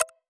check-on.wav